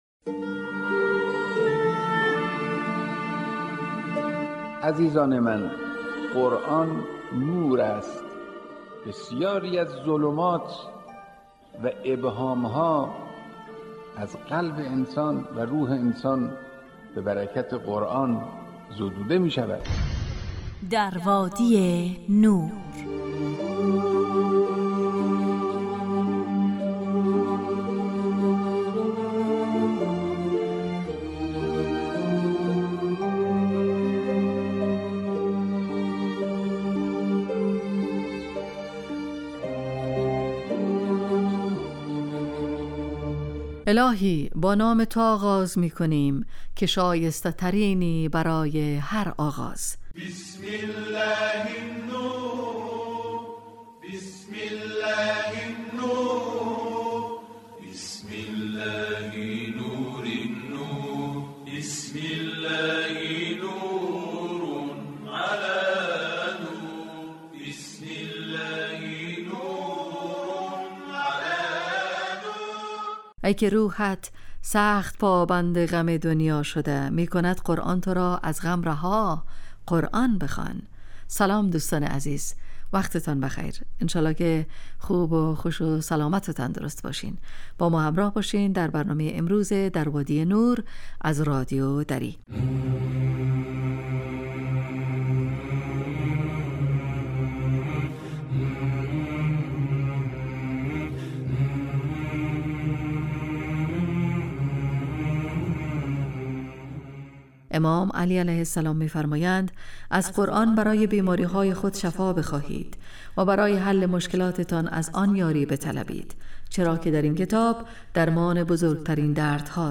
در وادی نور برنامه ای 35دقیقه ای با موضوعات قرآنی روزهای فرد: ( قرآن و عترت،طلایه داران تلاوت، ایستگاه تلاوت، دانستنیهای قرآنی، تفسیر روان و آموزه های زندگی ساز.